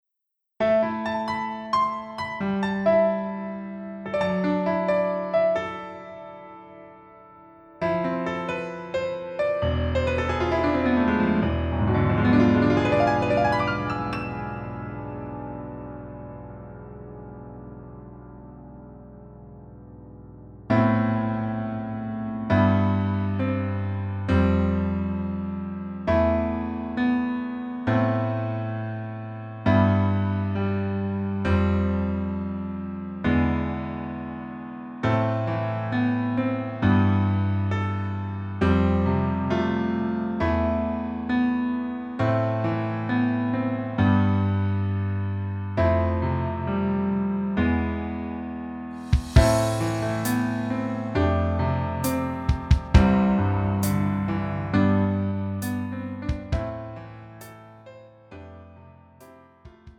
음정 남자키 4:29
장르 가요 구분 Pro MR